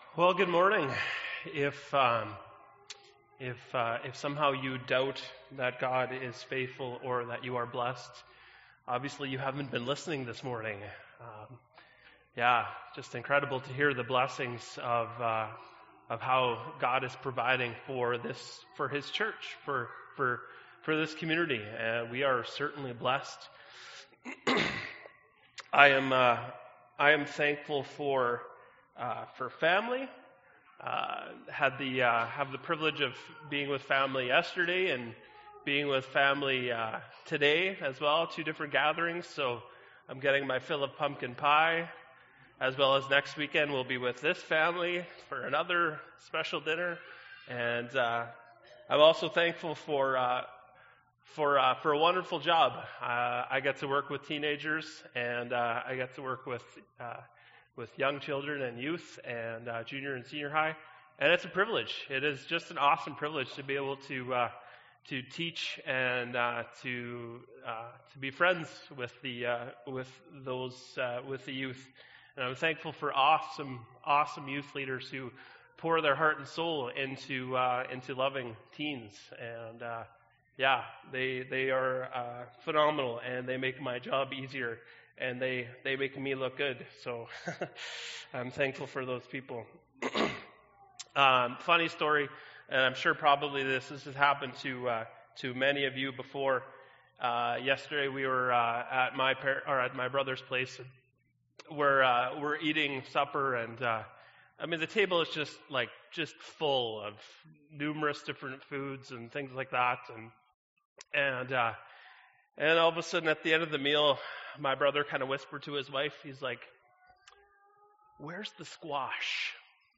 Oct. 11, 2015 – Sermon